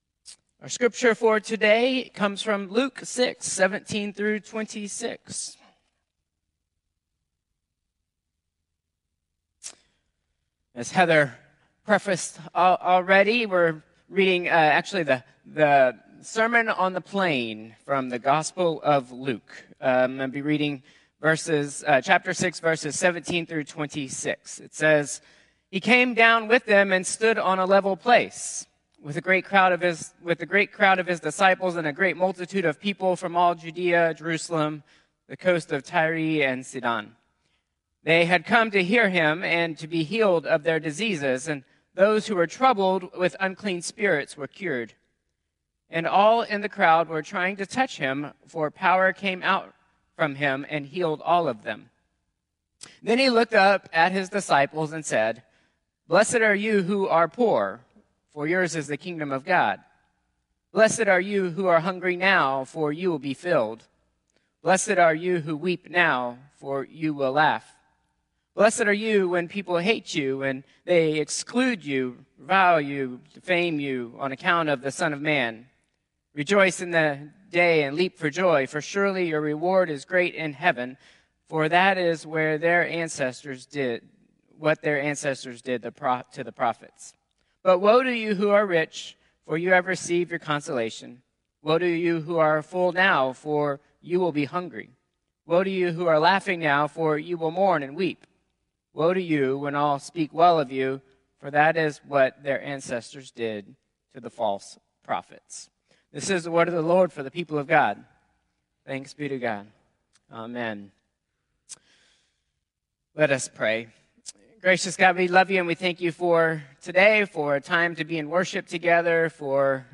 Contemporary Service 2/16/2025